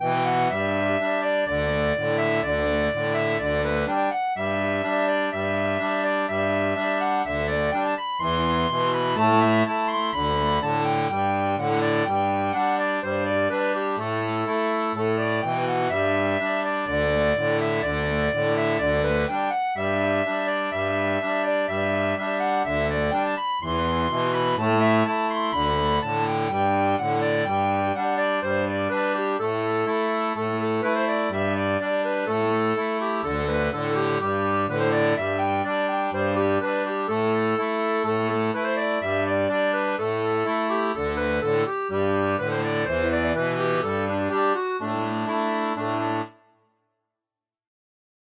Folk et Traditionnel